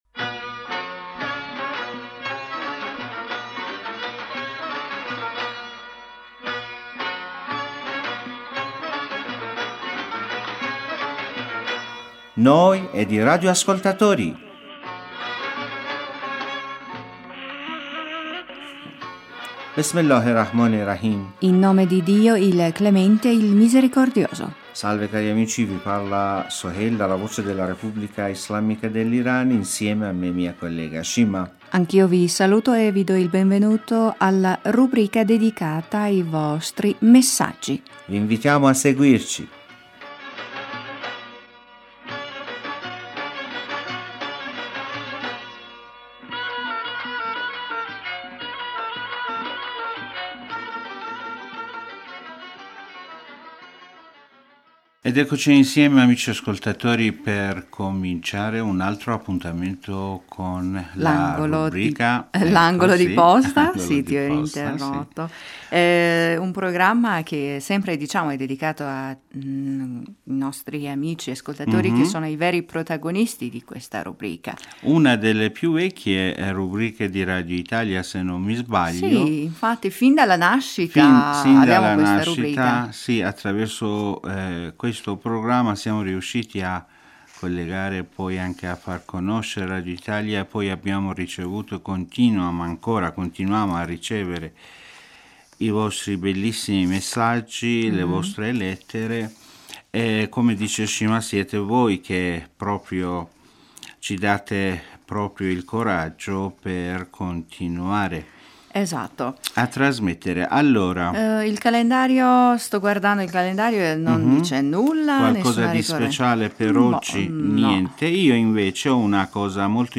In questa puntata potete ascoltare una bella canzone pop iraniana.